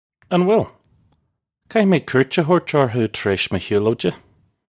Pronunciation for how to say
Un will? Kah-hee may koorch a hoarch orrhoo tar aysh muh hyoo-loja. (U)
This is an approximate phonetic pronunciation of the phrase.